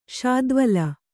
♪ śadvala